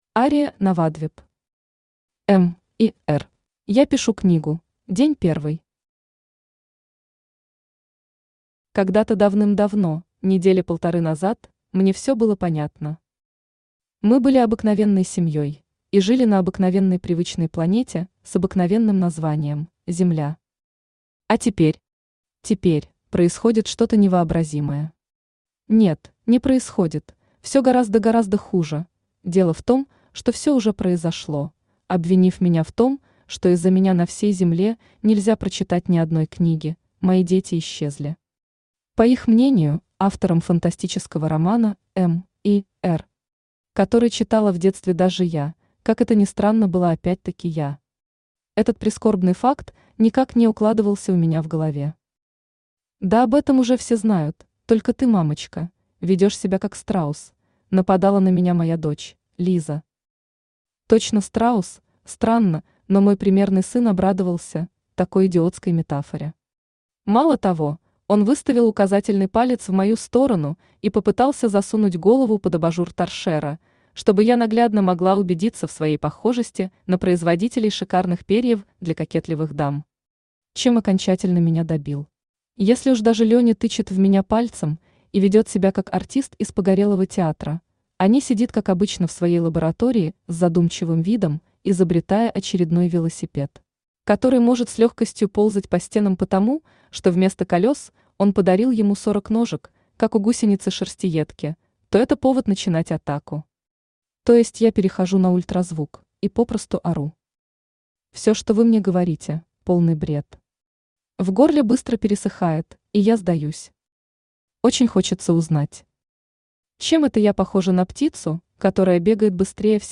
Аудиокнига М.И.Р | Библиотека аудиокниг
Aудиокнига М.И.Р Автор Ария Навадвип Читает аудиокнигу Авточтец ЛитРес.